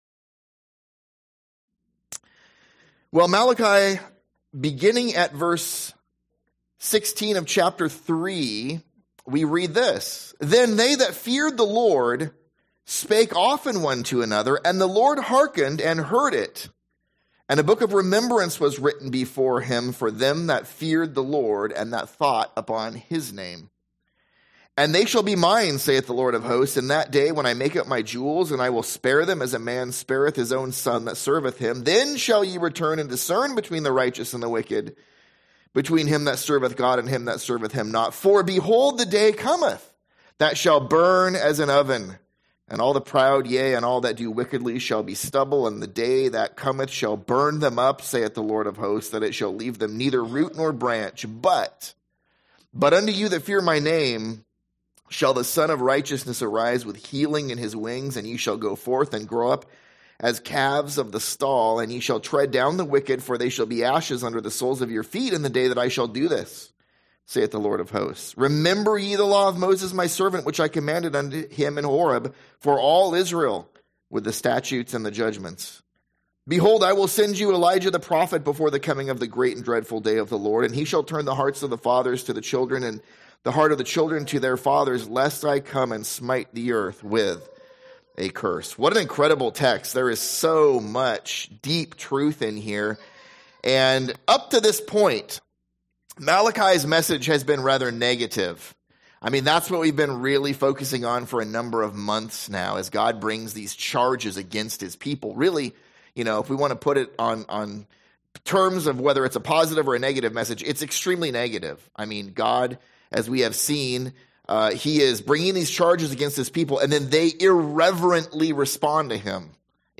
/ A Sunday-school series through the book of Malachi that considers the interaction between a loving God and a rebellious, apathetic people who care little about their relationship with Him.’